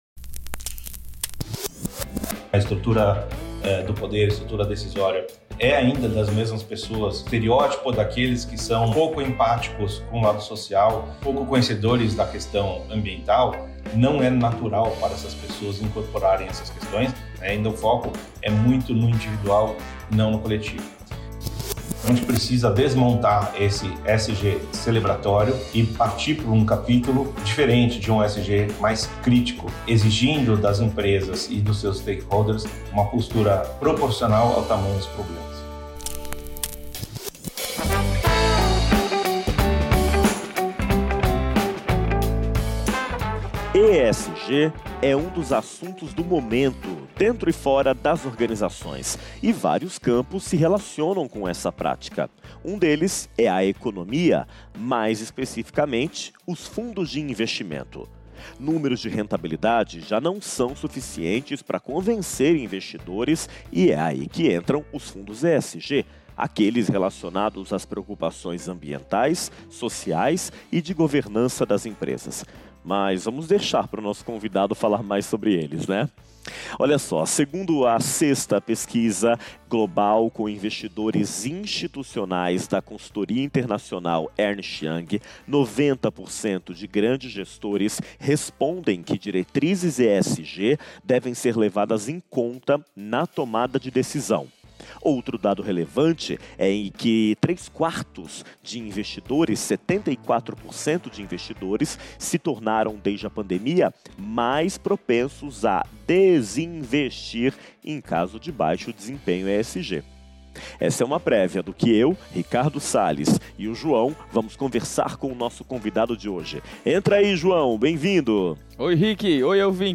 Foi uma conversa didática!